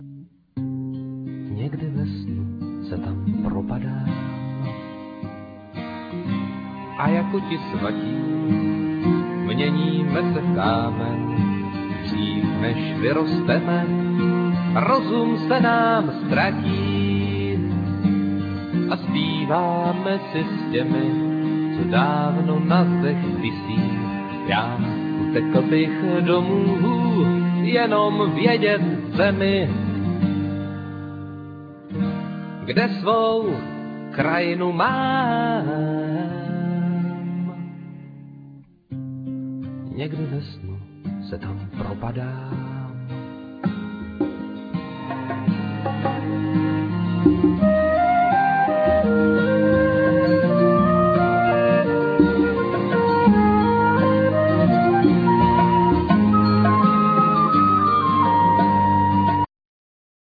Piano,Keyboards,Guitar,Trumpet,Vocal,etc
Cello,Saxophone,Vocal,etc
Flute,Piano,Keyboards,etc
Drums,Percussions,Vocal,etc